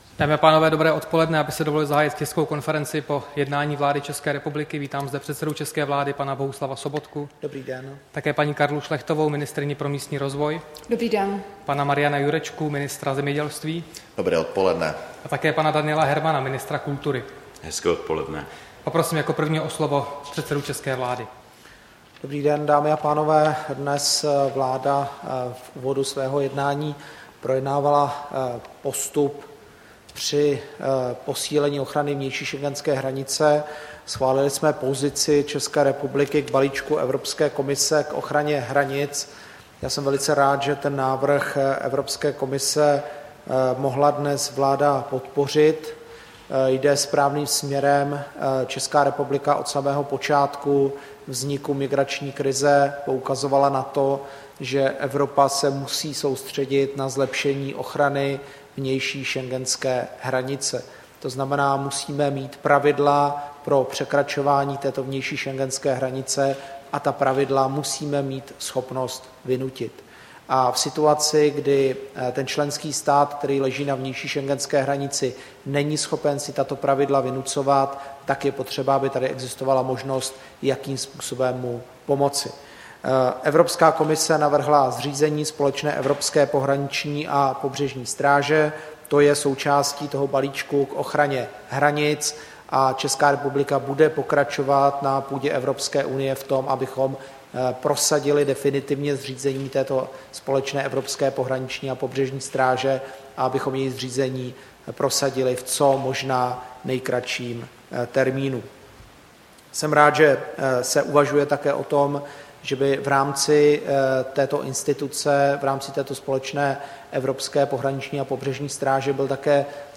Tisková konference po jednání vlády, 18. ledna 2016